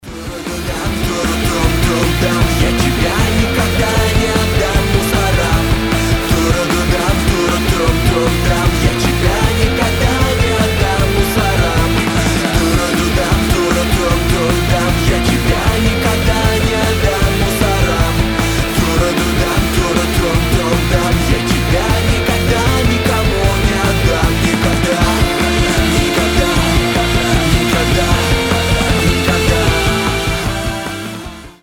быстрые
панк-рок
punk rock